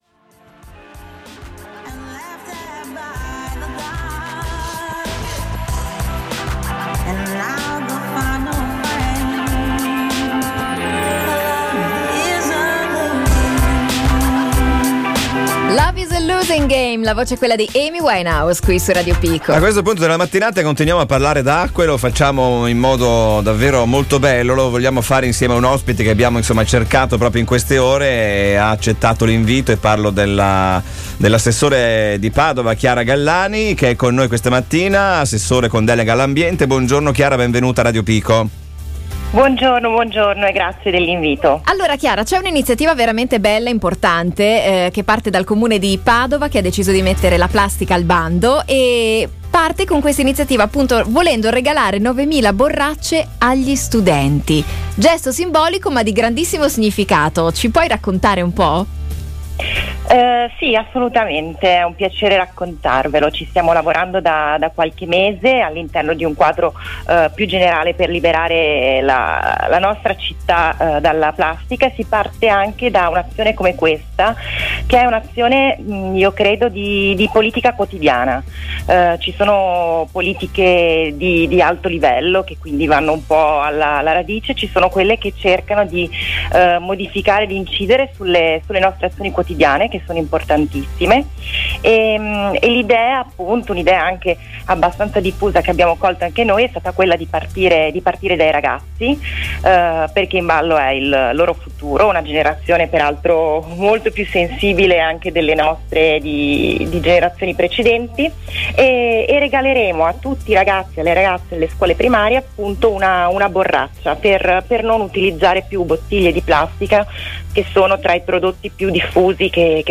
Abbiamo intervistato Chiara Gallani, assessore del Comune di Padova con delega all’ambiente, a proposito della lotta della città alla plastica. Lotta che parte dalle scuole, per questo anno scolastico, infatti, l’amministrazione regalerà 9mila borracce di metallo riutilizzabili, agli studenti delle scuole primarie.